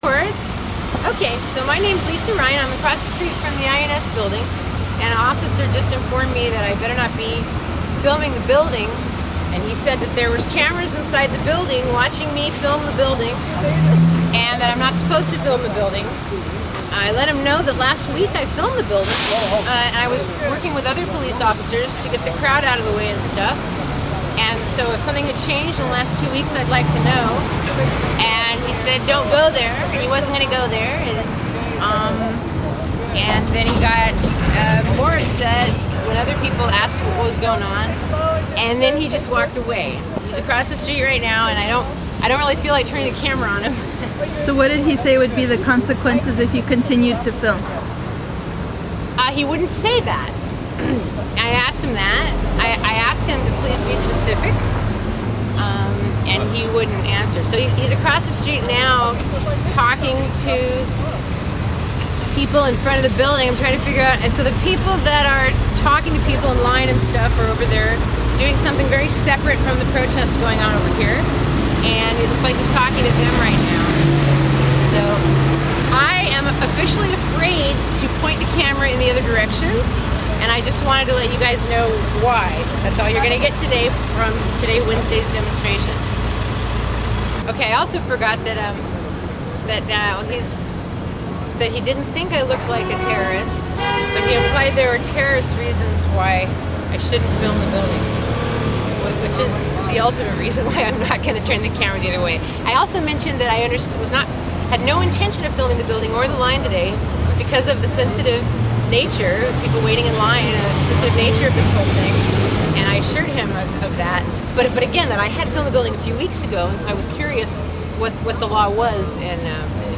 I didn't want to forget the details of what had taken place, so I had someone film me right afterwards while I had a fresh recollection...